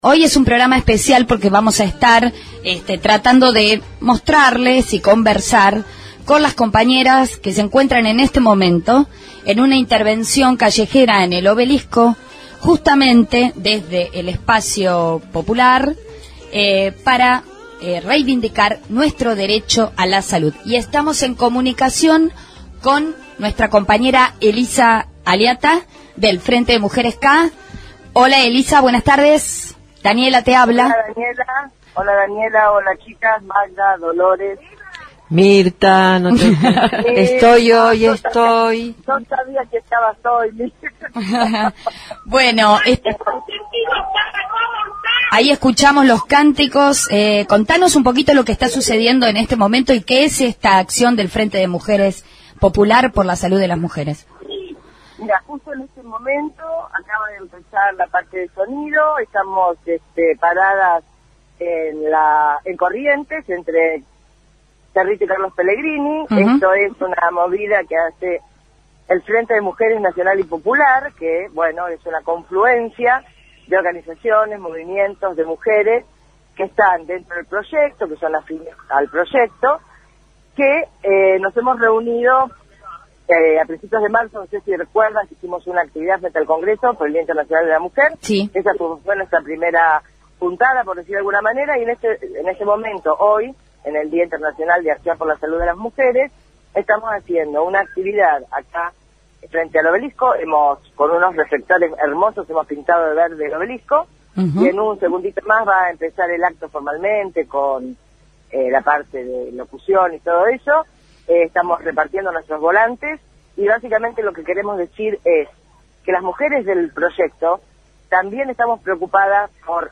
dialogó con